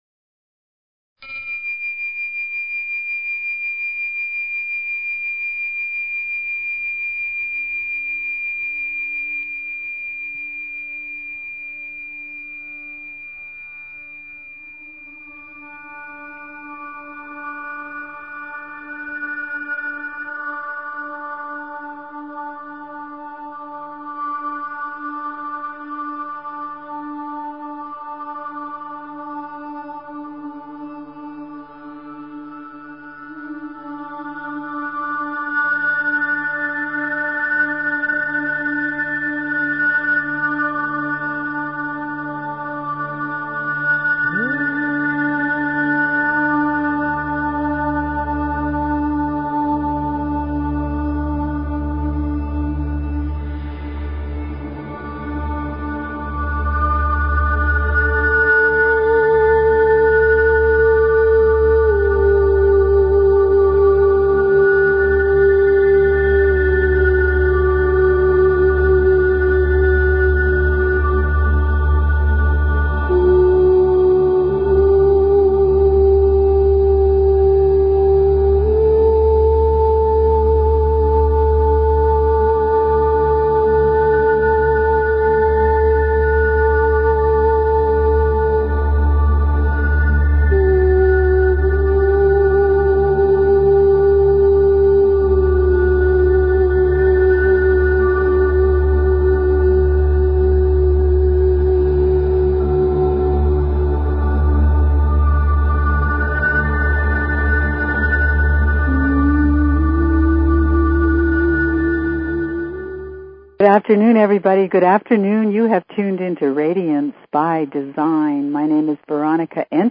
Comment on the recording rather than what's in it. We always start out with our SACRED GEOMETRIC MEDITATIONS to amp up your visualizations & vibrations, or find answers.